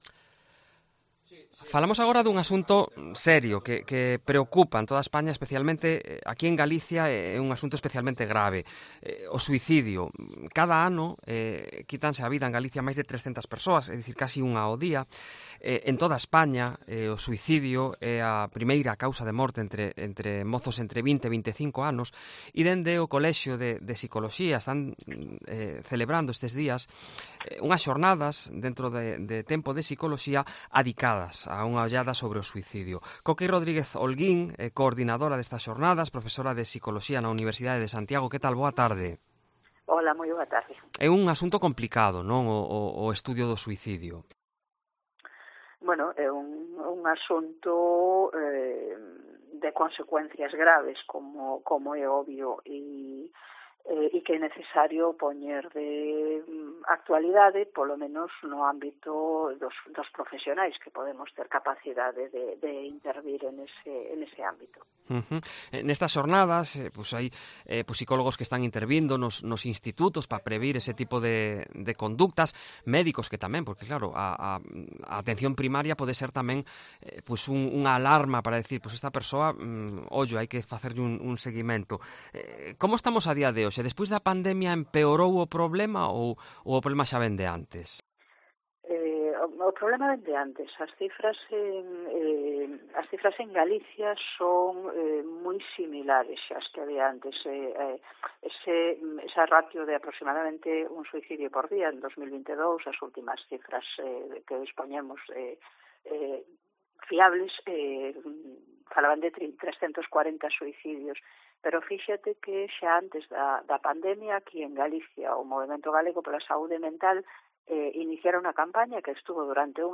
Una psicóloga habla claro sobre el suicidio: "No quieren morir..."